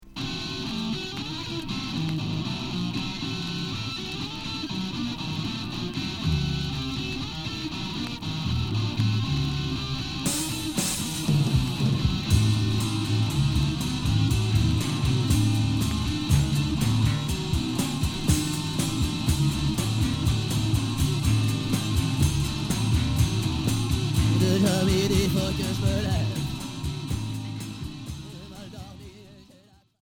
Punk rock Premier 45t